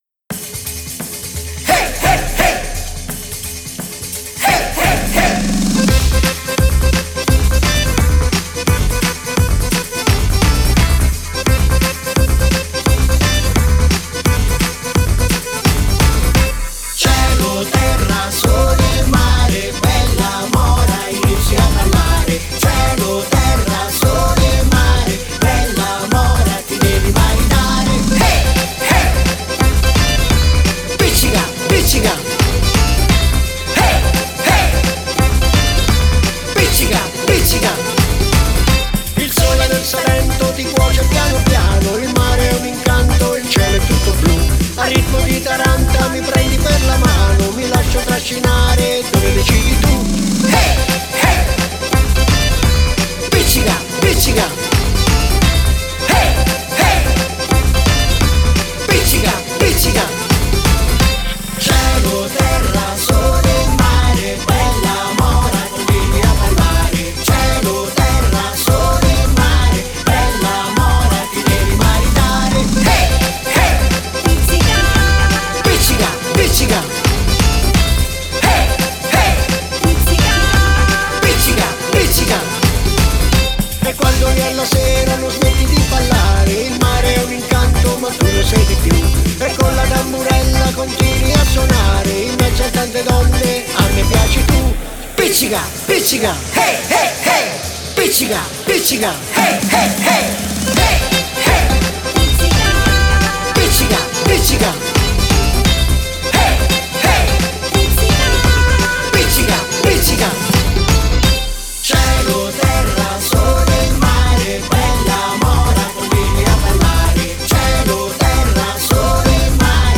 Pizzica